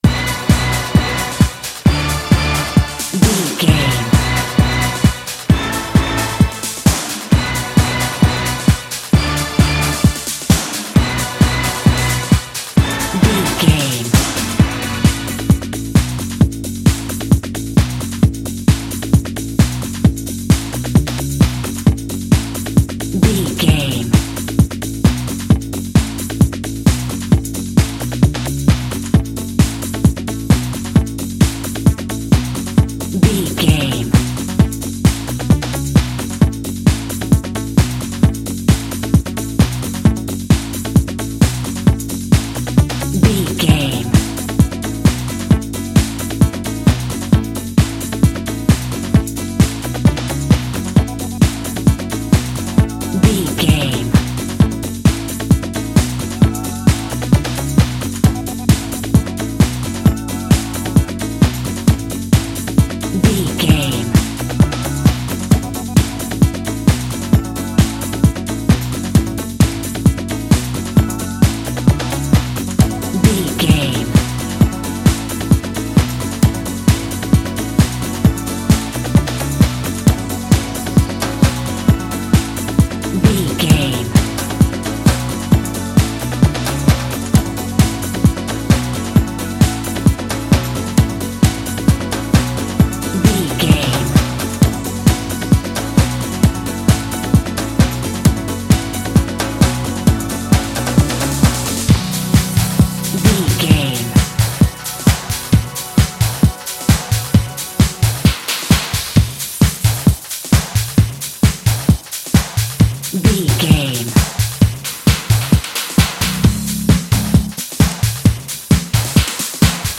Epic / Action
Fast paced
Ionian/Major
Fast
synthesiser
drum machine